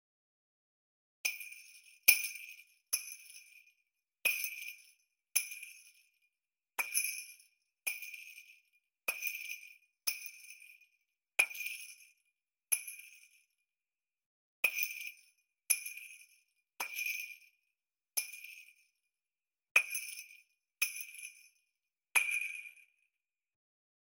Hit and bounce of the ball.
ball-movement.mp3